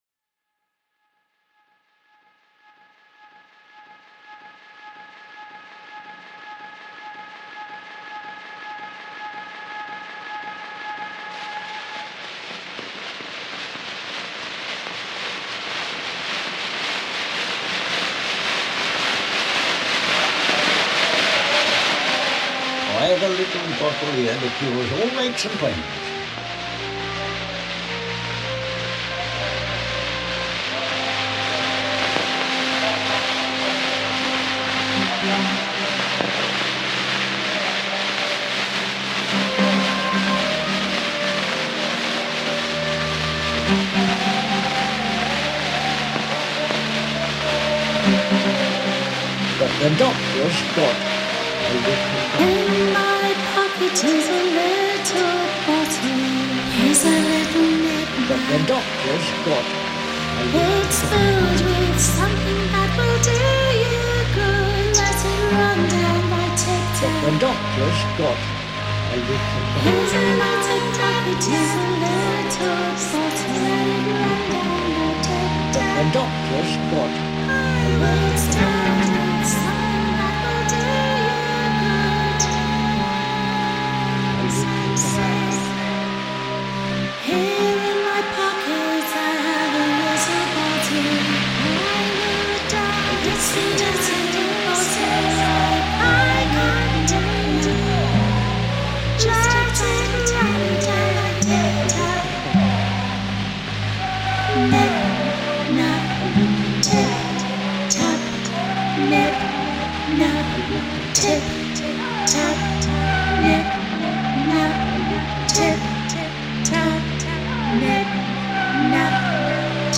The sound that I was allocated for this track was described as a Zande drinking song, from a collection of wax cylinder recordings of Zande songs, dances and spoken language made by social anthropologist Edward Evans-Pritchard in South Sudan between 1928 and 1930.
When I was listening to the recording, I was struck by the rhythmic drive of the cylinder, inserting itself into the song, unbidden.